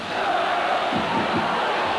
Semifinale Coppa dei Campioni